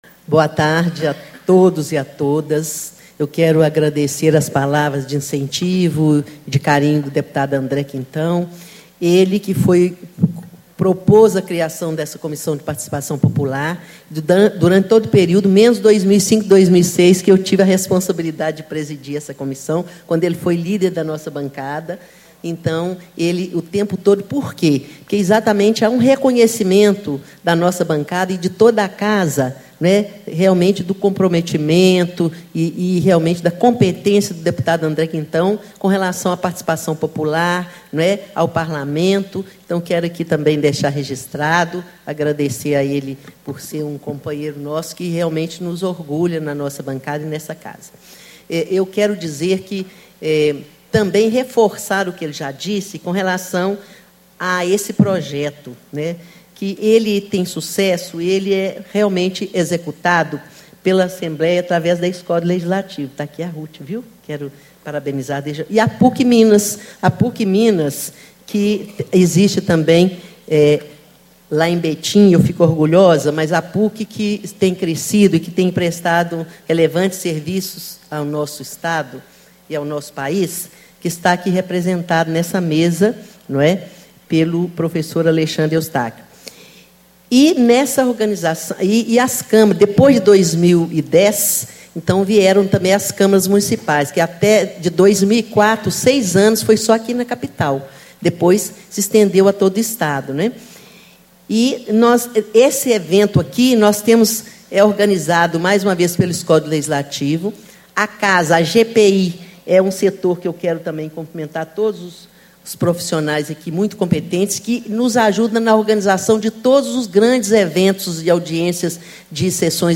Deputada Maria Tereza Lara, PT - Vice-Presidente da Comissão de Participação Popular
Discursos e Palestras